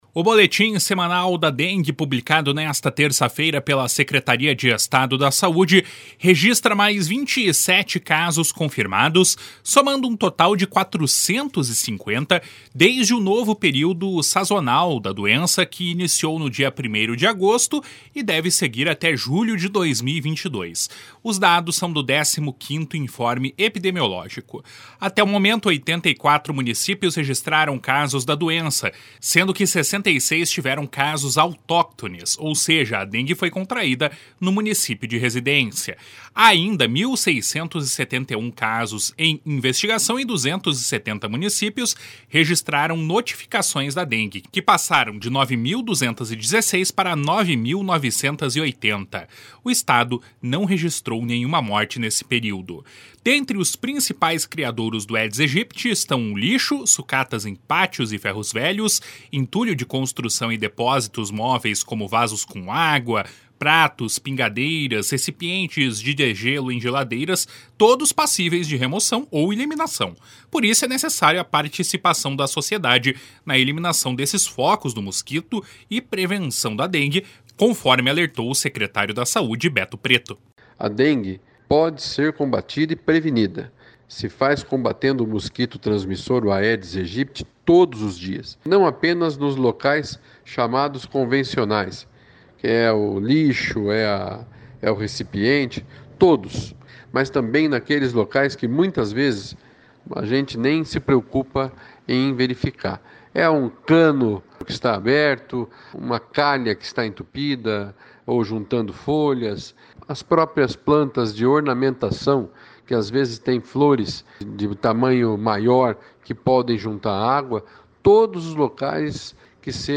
Por isso é necessário a participação da sociedade na eliminação de focos do mosquito e prevenção da dengue, conforme alertou o secretário da Saúde, Beto Preto.//SONORA BETO PRETO//